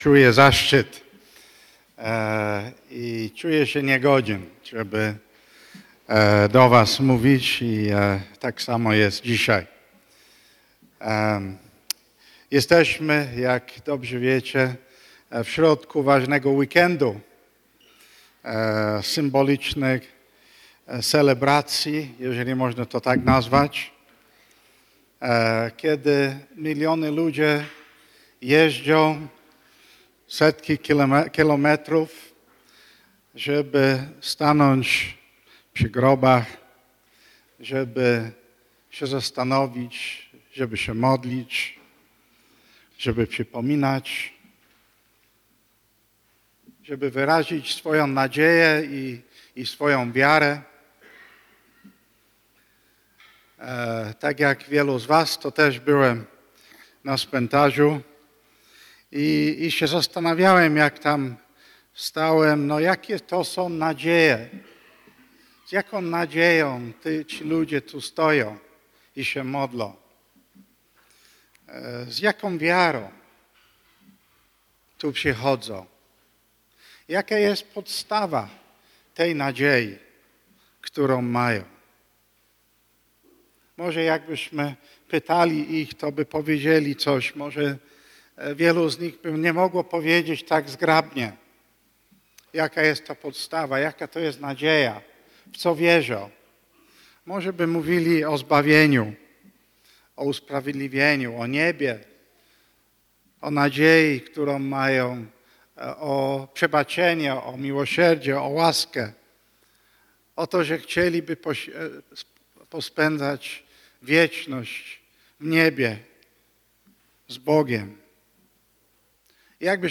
Nauczanie niedzielne Tematy